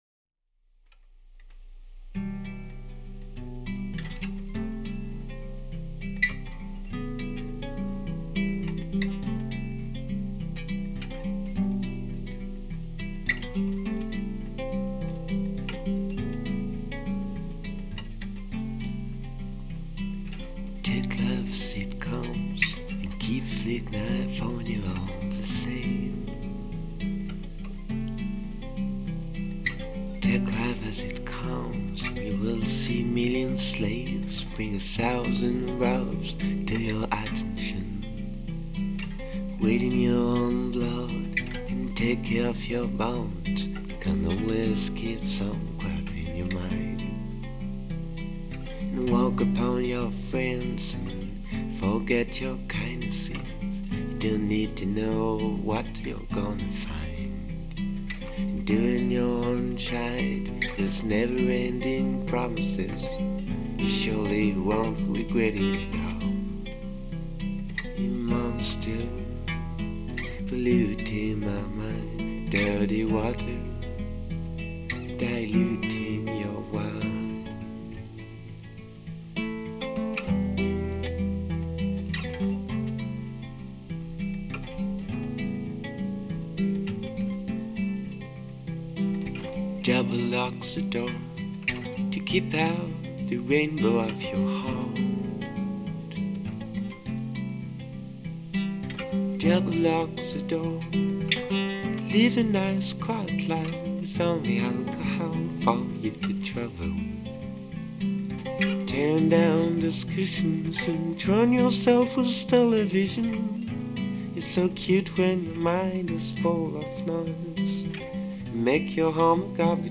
évolue dans un registre folk pop minimaliste
chansons folk intimiste